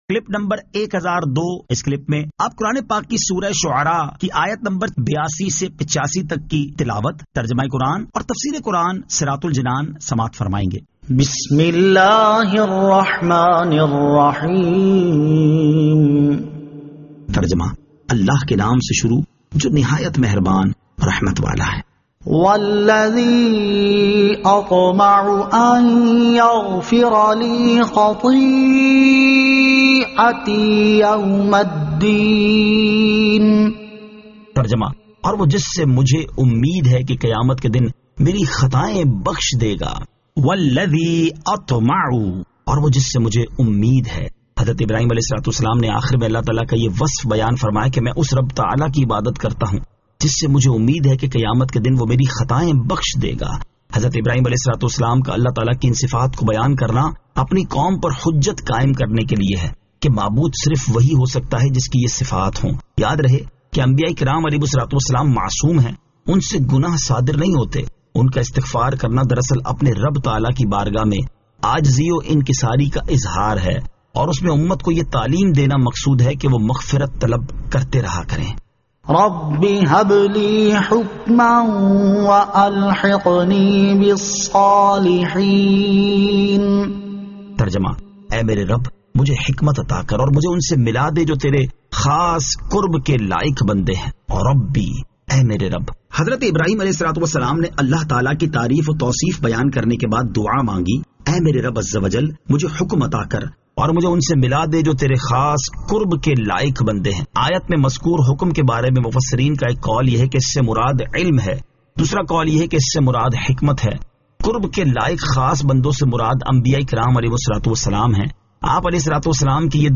Surah Ash-Shu'ara 82 To 85 Tilawat , Tarjama , Tafseer